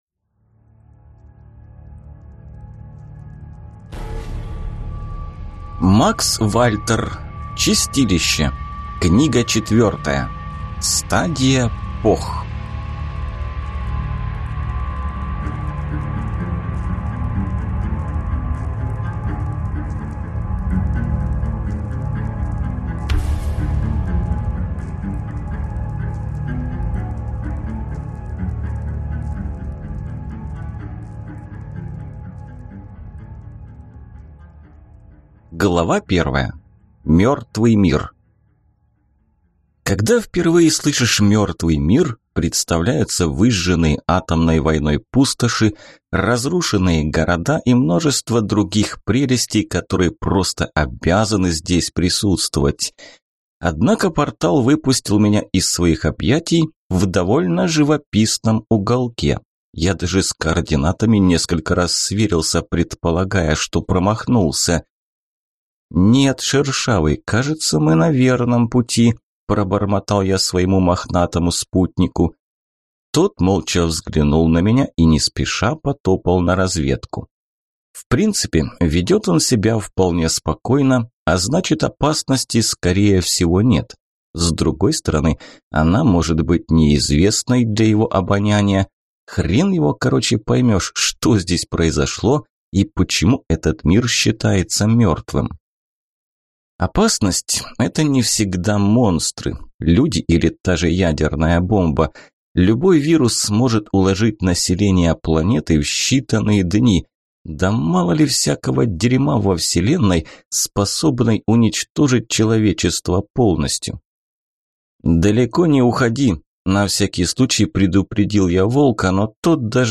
Аудиокнига Стадия Пох…